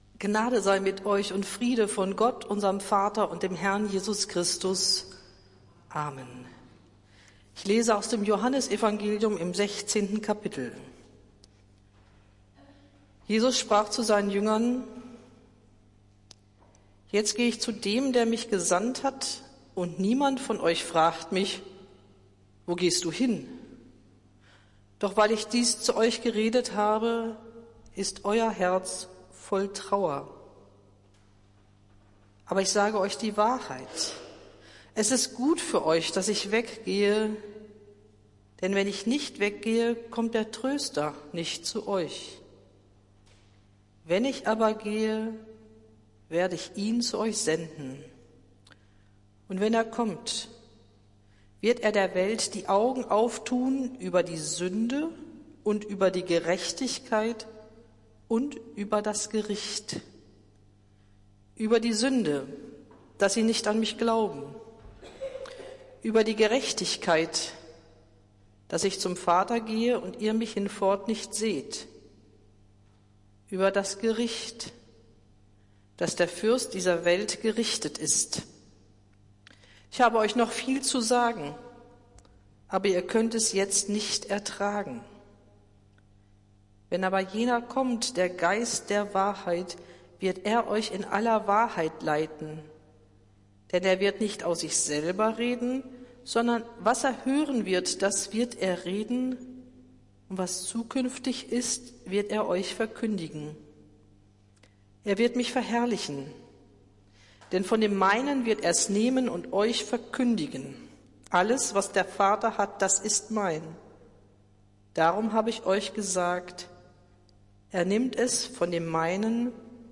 Predigt des Gottesdienstes aus der Zionskirche vom Sonntag, den 12. Mai 2024